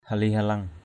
/ha-li-ha-lʌŋ/ (t.) đẹp đẽ = beau. beautiful. mbaok mata hali-haleng _O<K mt% hl}-hl$ mặt mày đẹp đẽ = qui a un beau visage.